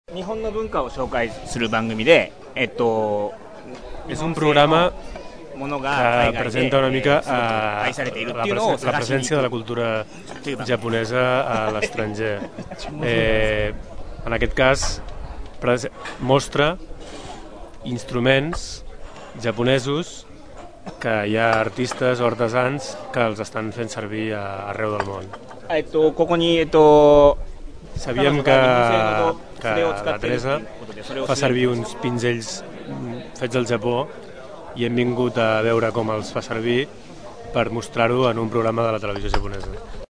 De fet, l’objectiu d’aquest programa és mostrar la presència de la cultura japonesa a l’estranger. En concret, busca artistes i artesans d’arreu del món que estan fent servir instruments fabricats al país del Sol Naixent. Així ho ha explicat als micròfons de Ràdio Tordera un dels periodistes del programa nipó.